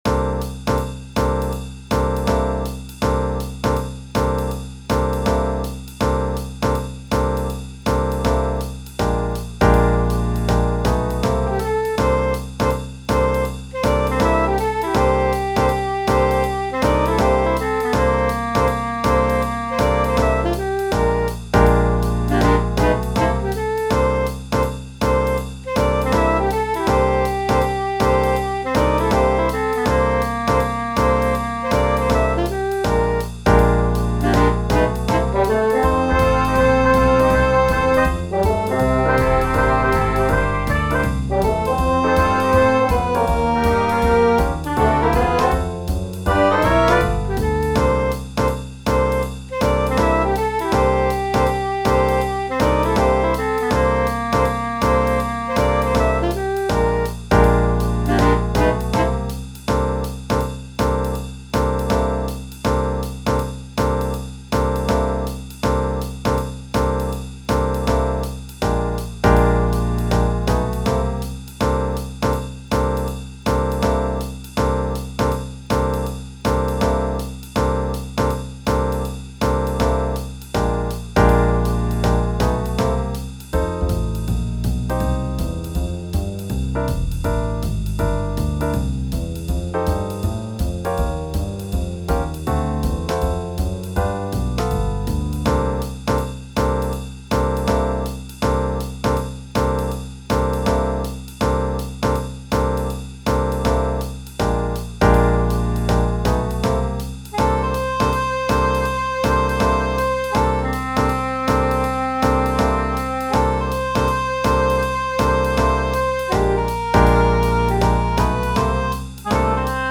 MP3 de travail + lent